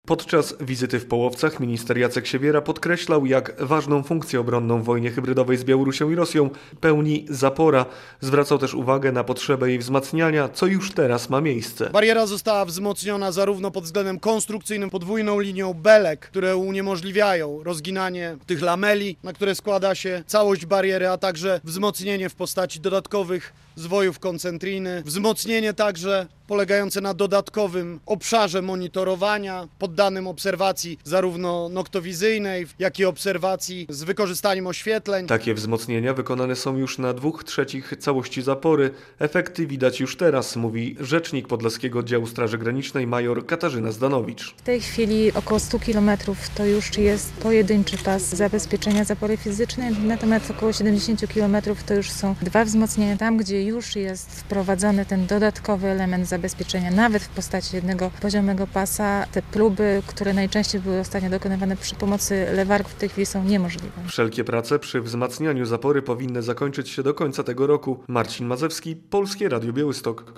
Nie wolno nam utracić ostrości widzenia i zapomnieć o tym, z którego kierunku przychodzi największe zagrożenie dla RP - powiedział w piątek (11.10) na granicy z Białorusią w Połowcach szef Biura Bezpieczeństwa Narodowego Jacek Siewiera. Spotkał się tam ze służbami i oglądał wzmacniane zabezpieczenia na tej granicy.
relacja